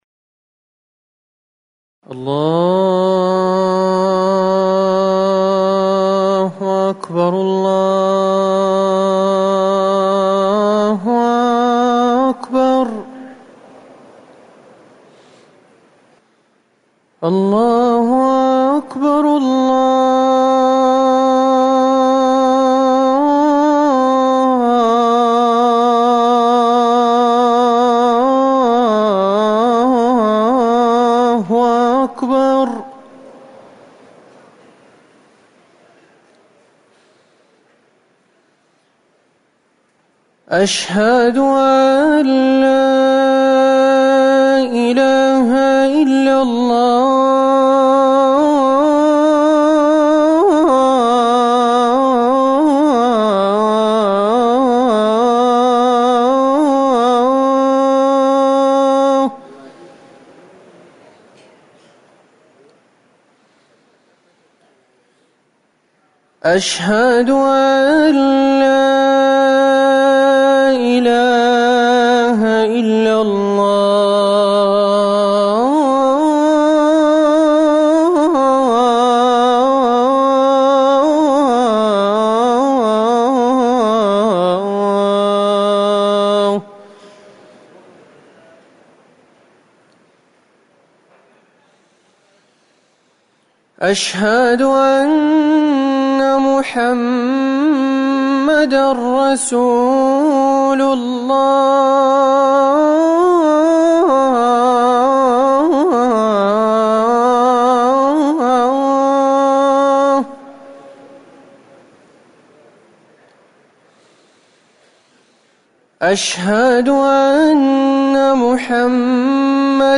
أذان الجمعة الأول
تاريخ النشر ١٩ صفر ١٤٤١ هـ المكان: المسجد النبوي الشيخ